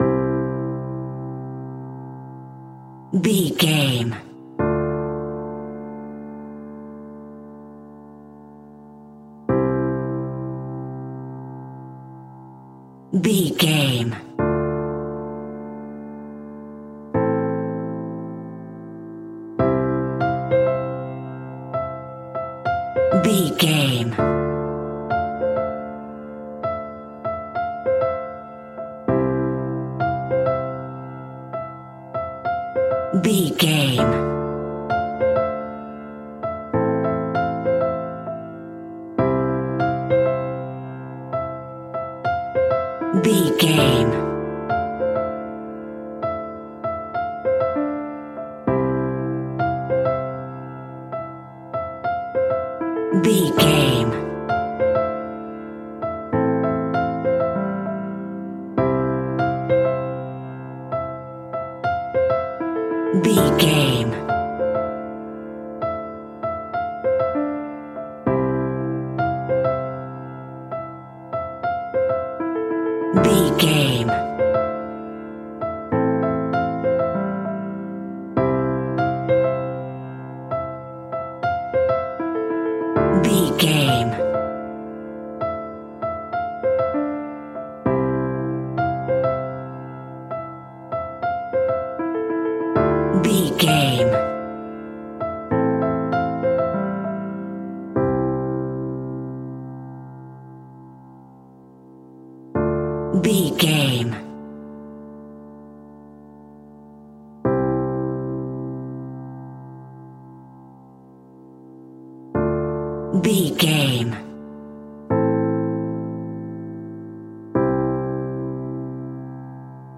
Melodic and light piano music in a major key.
Regal and romantic, a classy piece of classical music.
Ionian/Major
soft